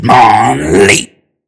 infinitefusion-e18/Audio/SE/Cries/HITMONLEE.mp3 at releases-April